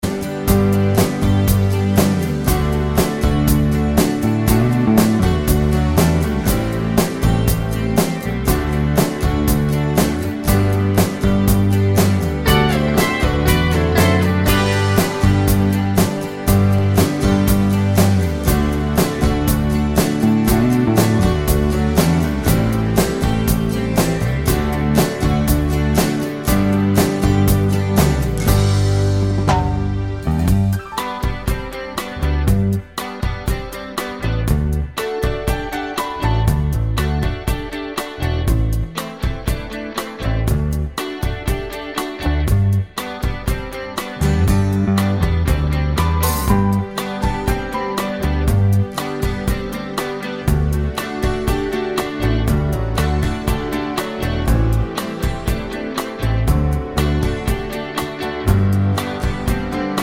no Backing Vocals Country (Male) 3:48 Buy £1.50